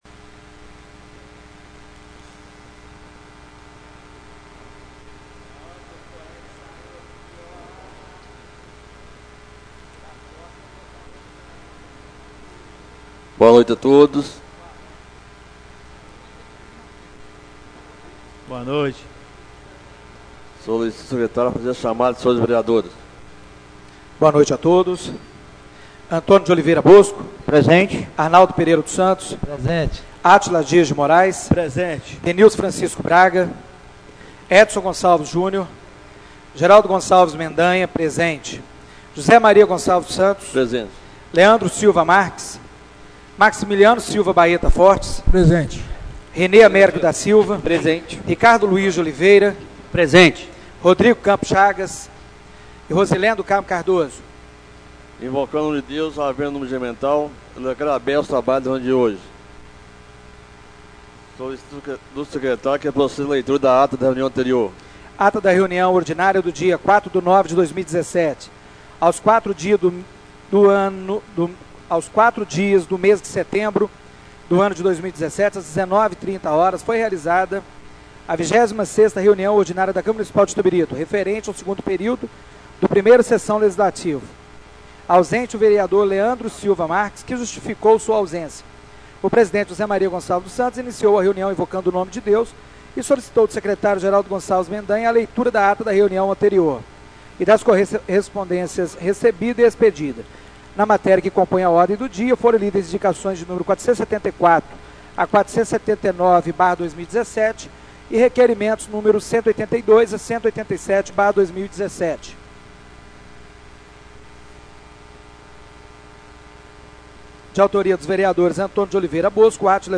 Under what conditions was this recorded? Reunião Ordinária do dia 18/09/2017